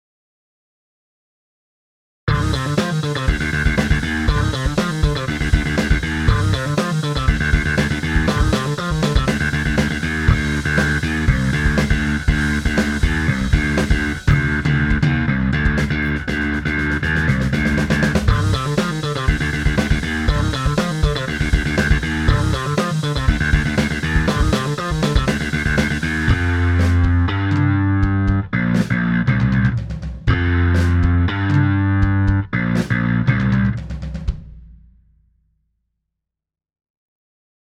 Dabei handelt es sich um einen fünfsaitigen Bass, der sich vor allen Dingen für Rock und Metal eigenen soll.
Für die Klangbeispiele habe ich Bassläufe mit unterschiedlichen Presets eingespielt.